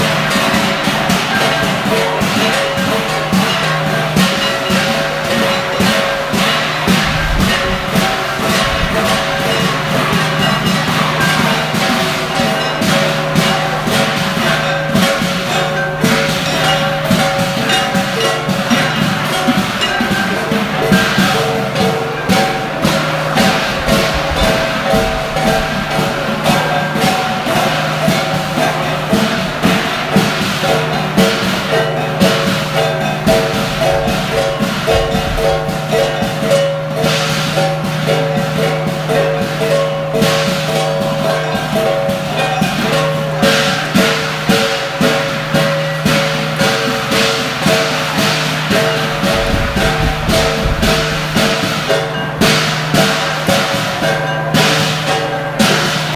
Chinese-Drumming.m4a